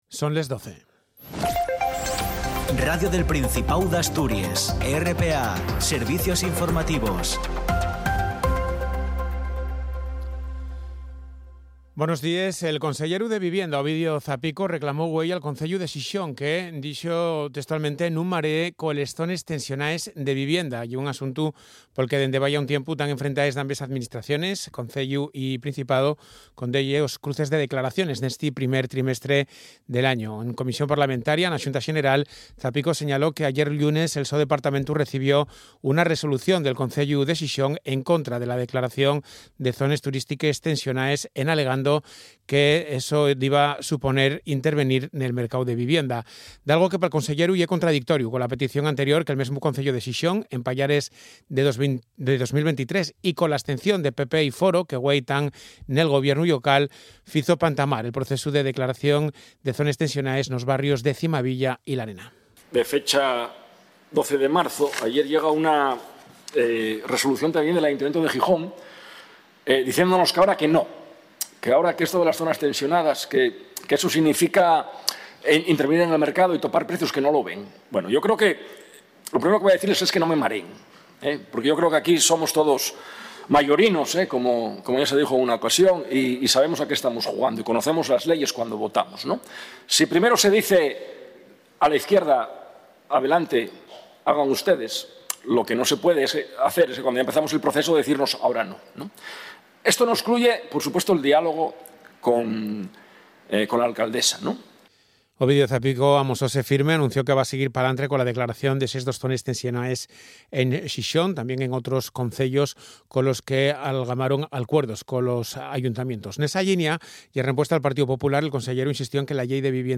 El boletín de las 12:00 horas tiene una duración de 10 minutos y se emite en asturiano. La actualidad general del día en nuestra lengua.